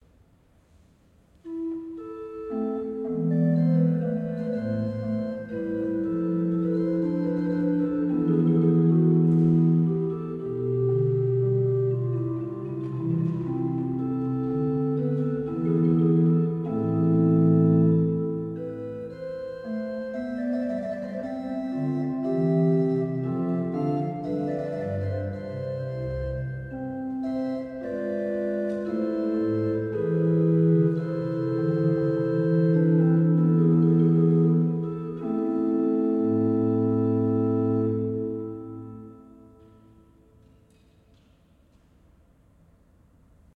Bourdon 8'
Flûte conique 4'
Tremblant doux.
Vous trouverez un très bel orgue-positif avec une construction artisanale en chêne massif avec 4 registres.
A' = 440 Hz./18°. Tempérament, inégale, Valotti